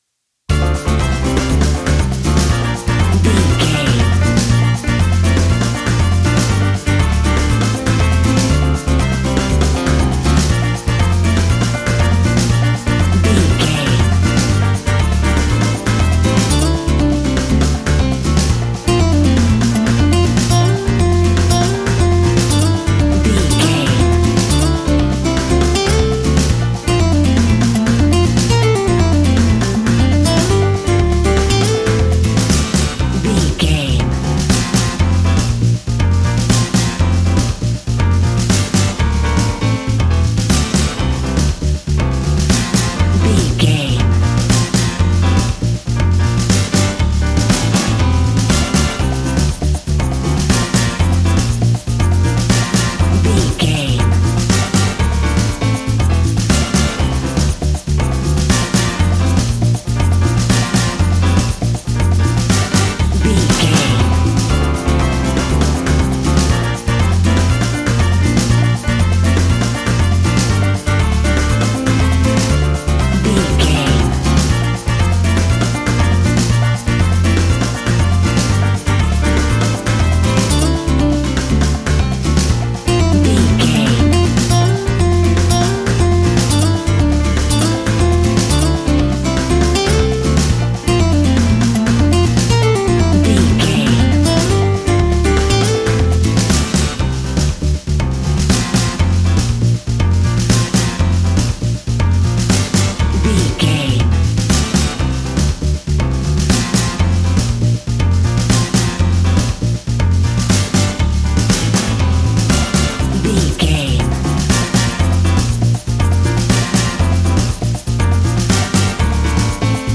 An exotic and colorful piece of Espanic and Latin music.
Aeolian/Minor
D
flamenco
maracas
percussion spanish guitar